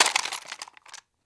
grenade_reloadgarand1.wav